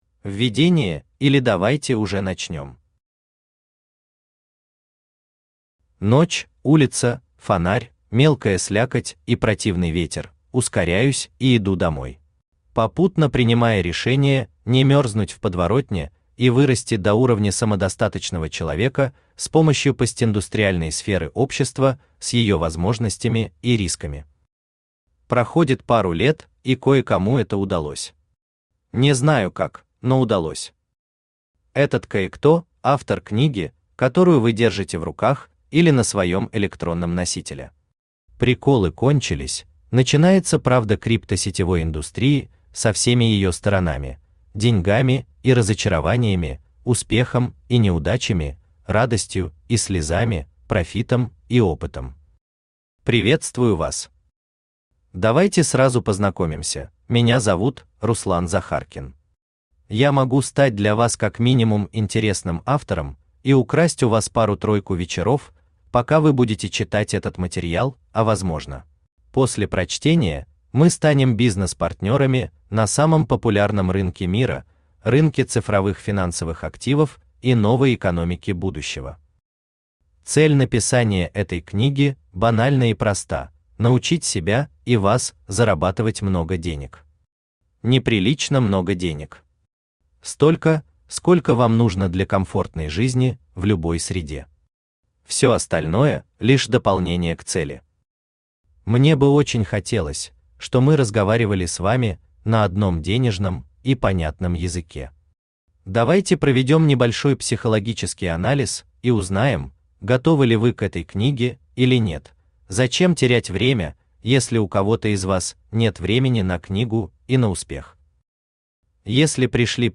Aудиокнига Криптовалюта и сетевой бизнес: как заработать первый миллион Автор Руслан Игоревич Захаркин Читает аудиокнигу Авточтец ЛитРес.